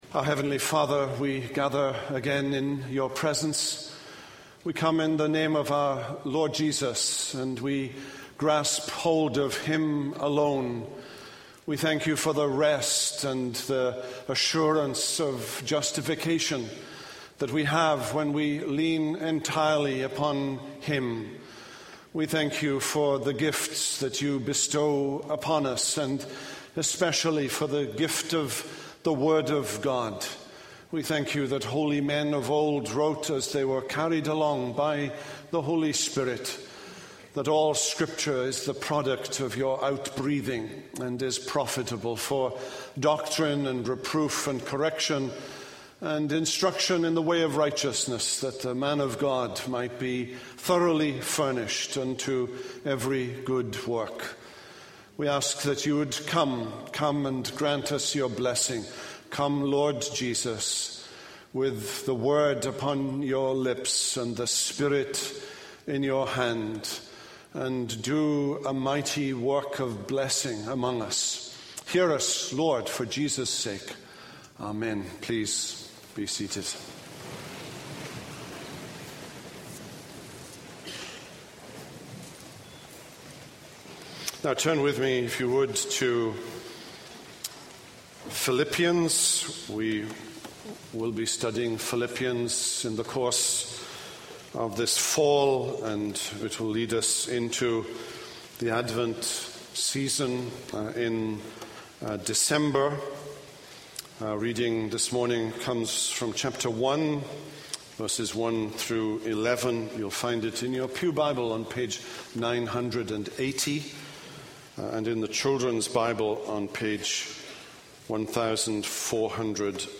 This is a sermon on Philippians 1:1-11.